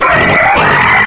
pain1.ogg